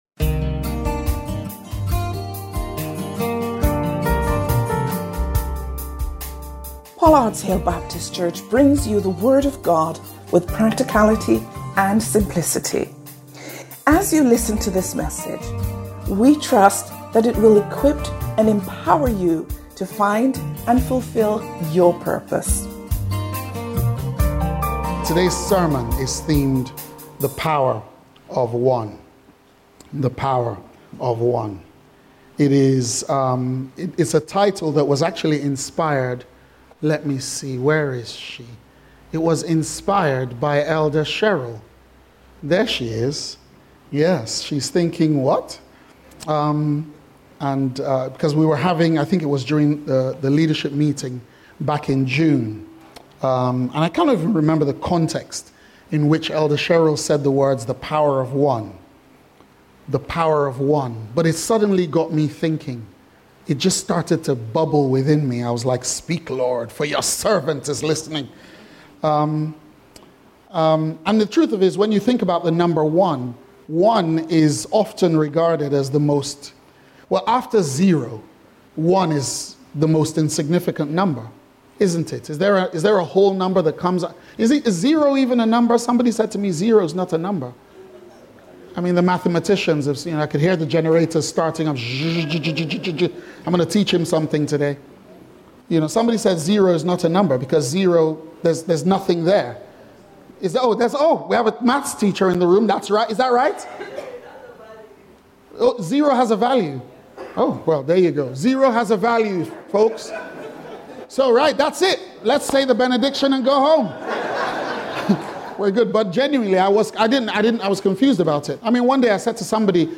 Pollards Hill Baptist Church